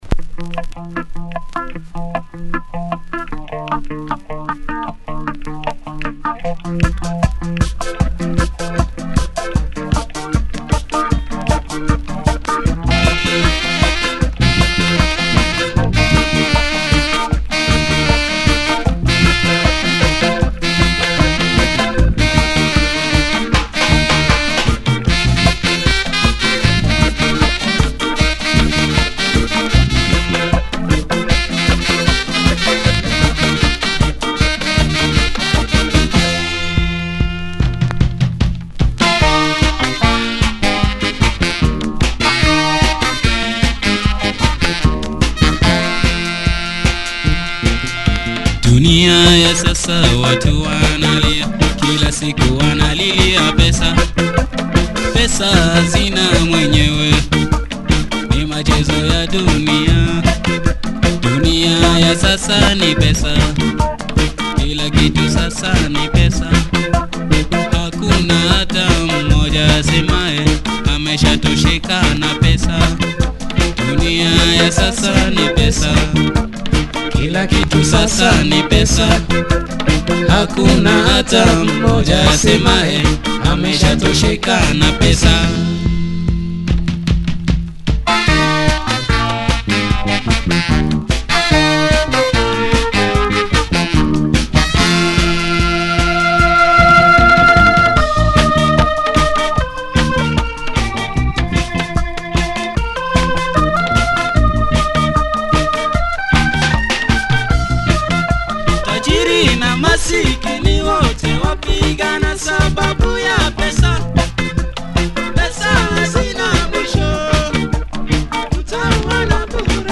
Superb reggae flavored Swahili bumper